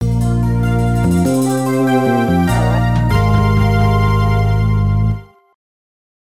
The Battle Mode intro fanfare